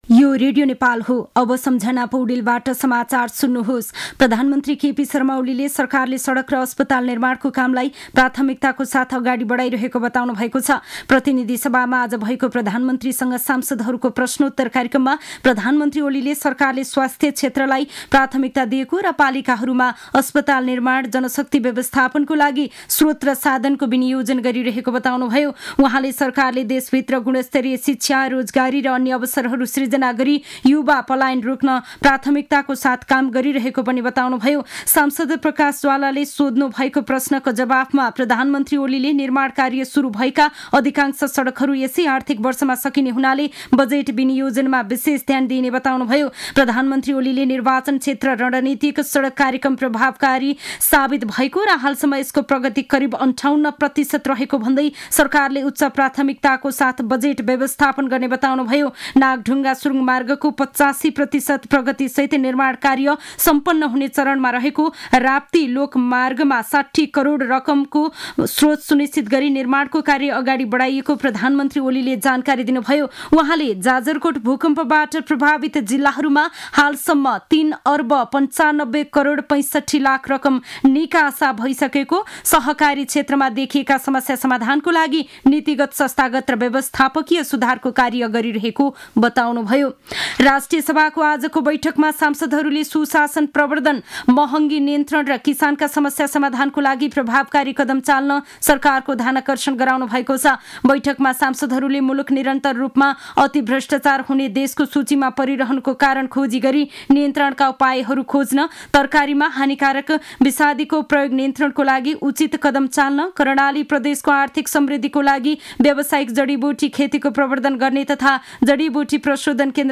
दिउँसो ४ बजेको नेपाली समाचार : ५ फागुन , २०८१